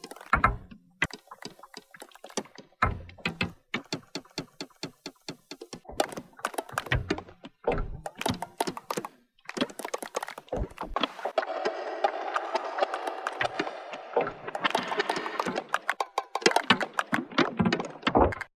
a hybrid between edgy improv takes and deep IDM-ish grooves
All this was done on a live setup without a laptop.